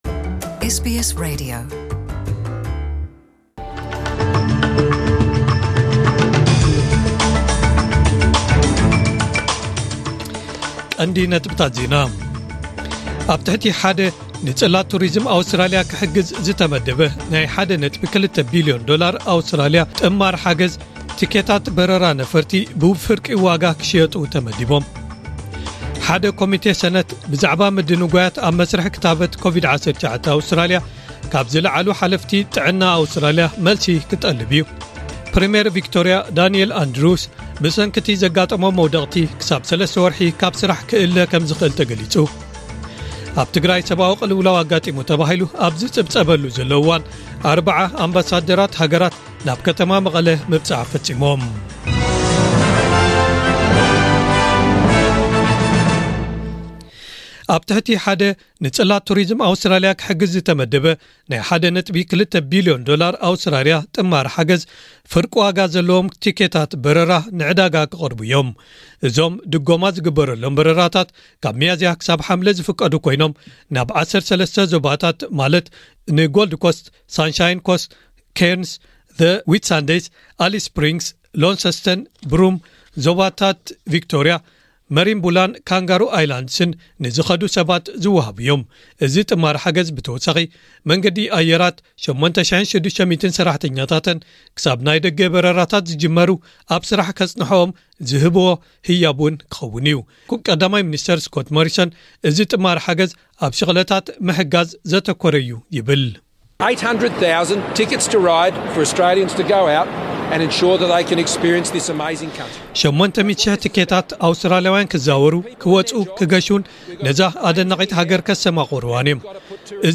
ዕለታዊ ዜና ኤስቢኤስ ትግርኛ (11 መጋቢት 2021)